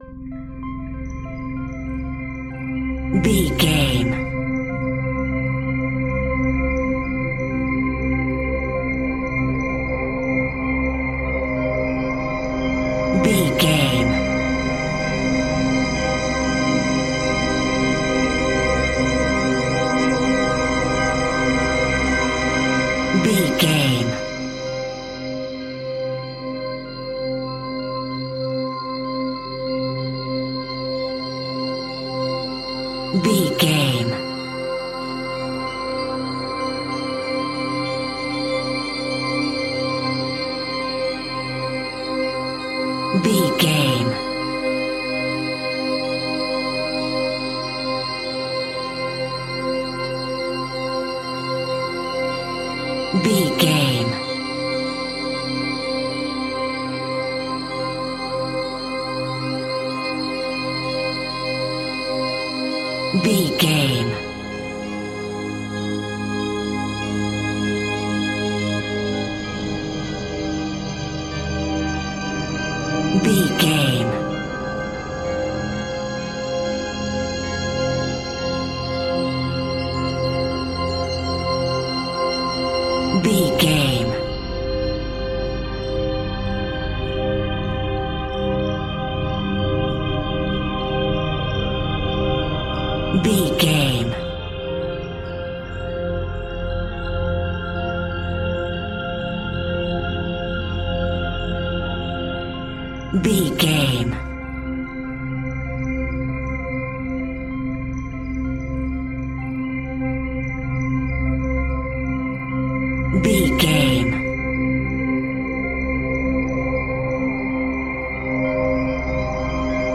Horror Atmosphere Sounds.
Aeolian/Minor
Slow
ominous
haunting
eerie
synthesiser
strings
Horror synth
Horror Ambience